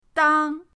dang1.mp3